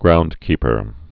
(groundkēpər)